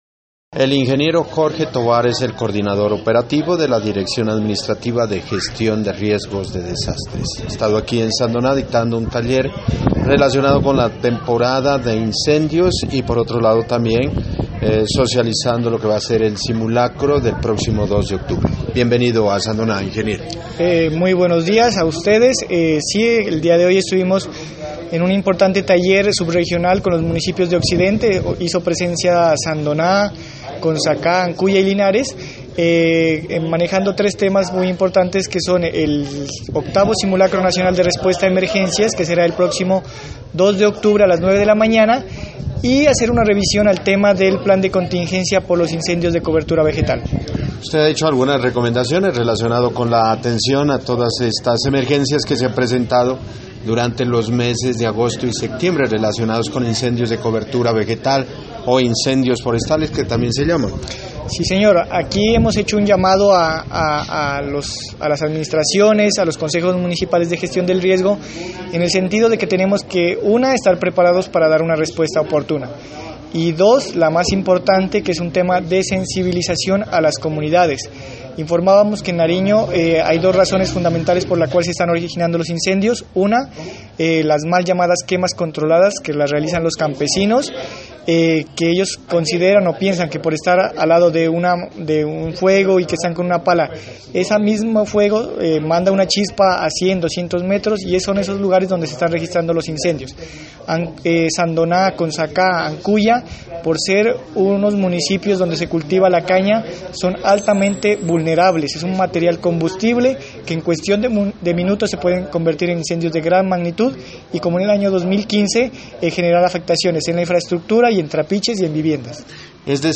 Dirección de gestión del riesgo dictó taller en Sandoná